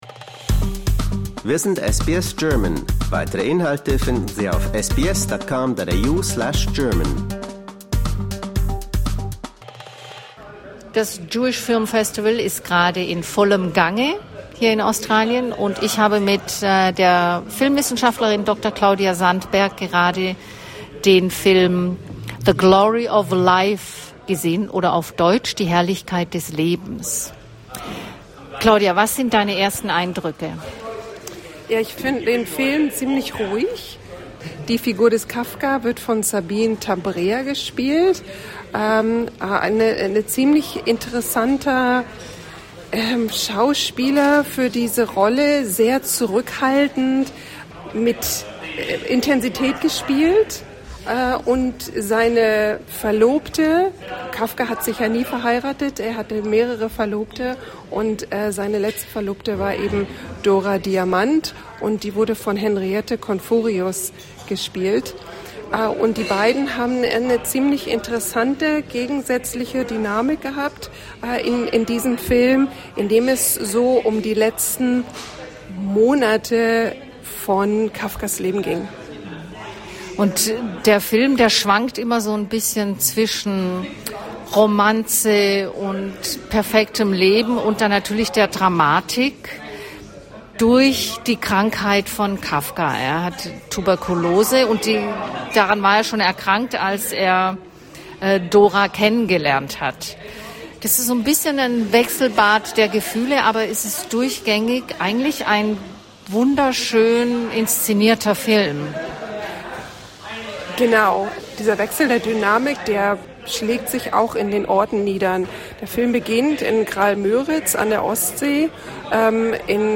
For more stories, interviews and news from SBS German, explore our podcast collection here .